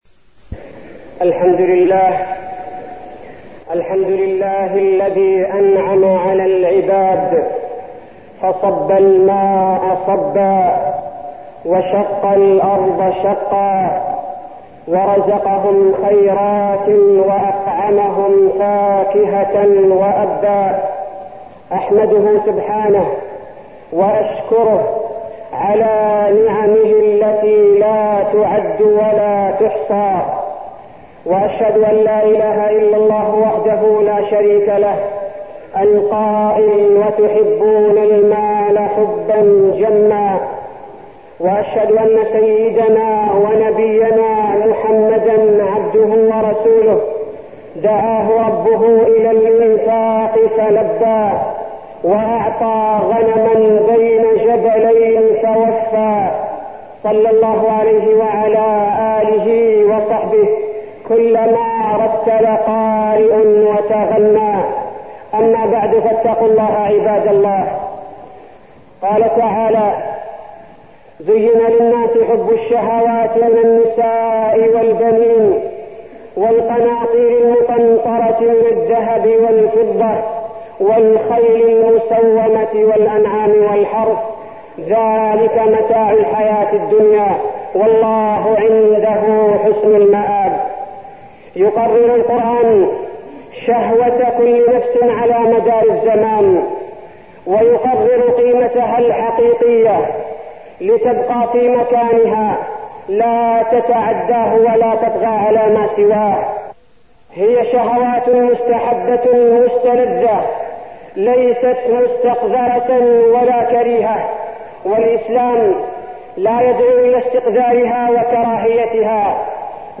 تاريخ النشر ٢٨ رجب ١٤١٥ هـ المكان: المسجد النبوي الشيخ: فضيلة الشيخ عبدالباري الثبيتي فضيلة الشيخ عبدالباري الثبيتي فتنة المال